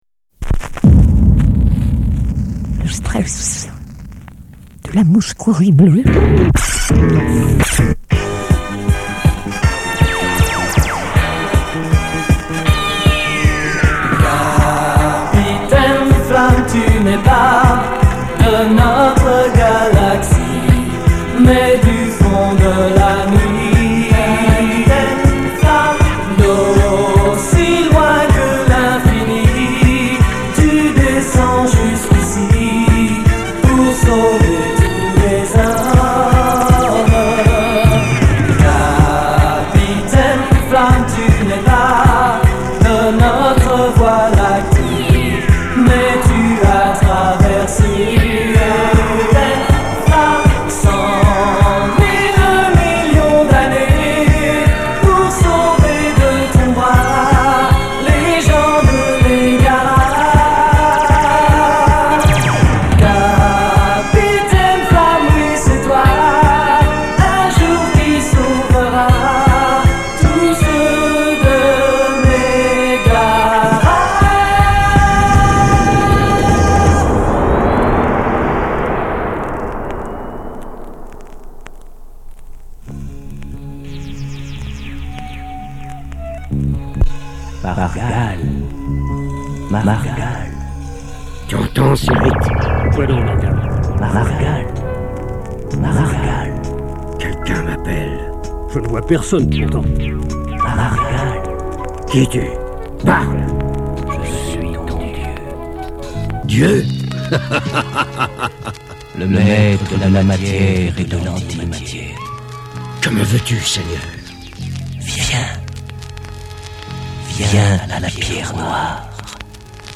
diffusion originale : radio Accords le 04.10.1996 à partir de 23h et quelques secondes.